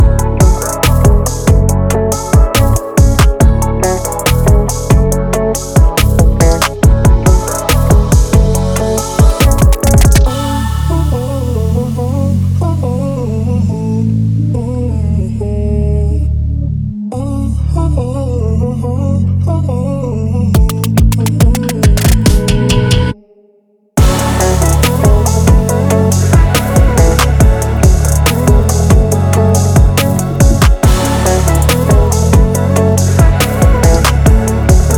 K-Pop Pop Soundtrack
Жанр: Поп музыка / Соундтрэки